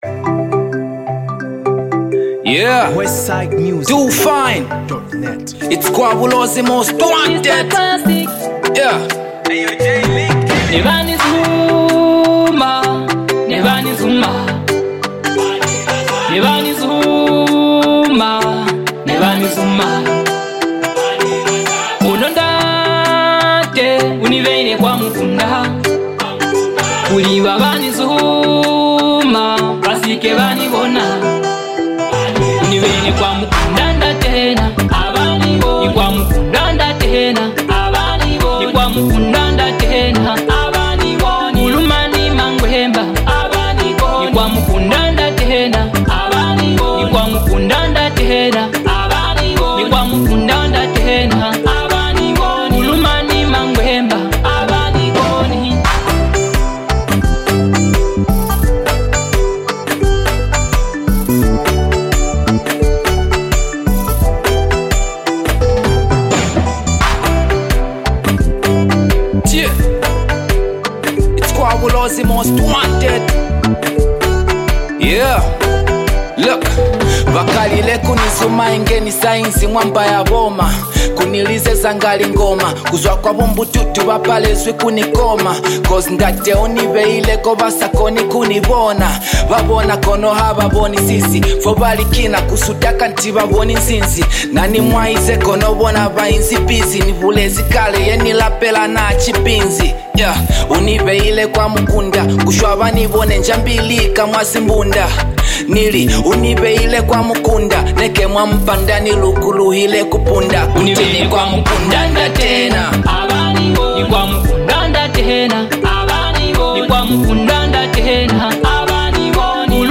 Genre: Afro Pop, Zambia Songs